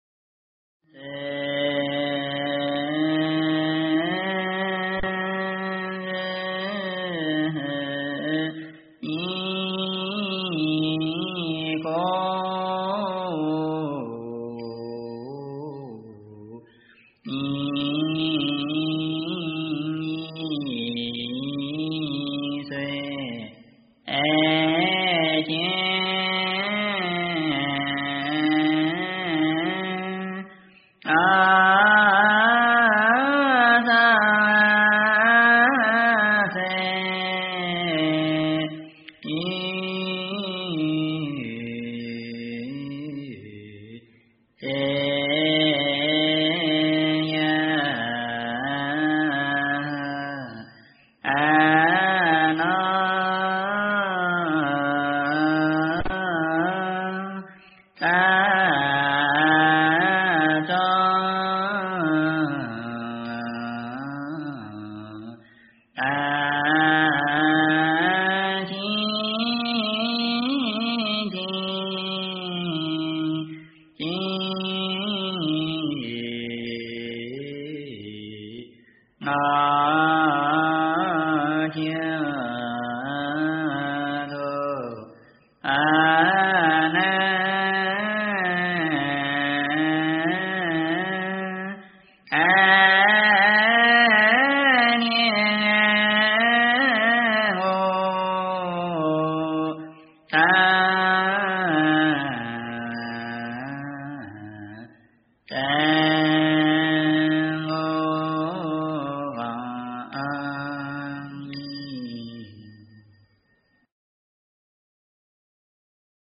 普贤菩萨警众言 - 诵经 - 云佛论坛